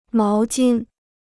毛巾 (máo jīn): Handtuch; Tuch.